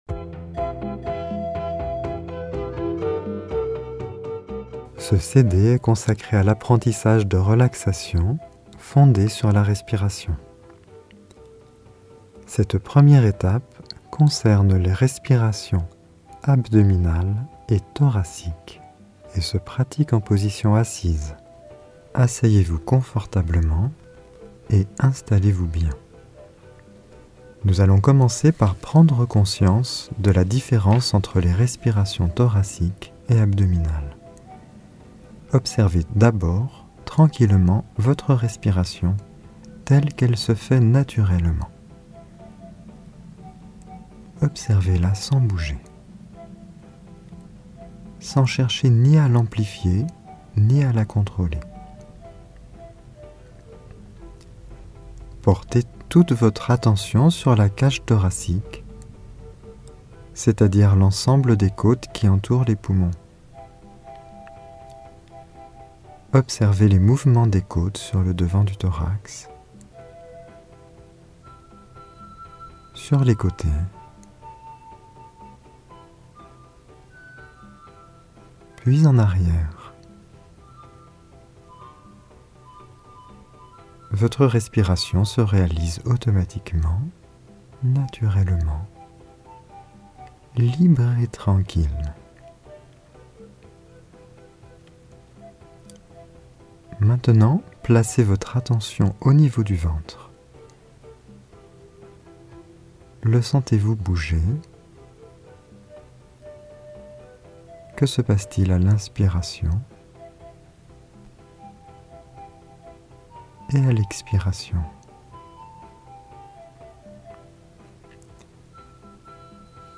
Genre : Meditative.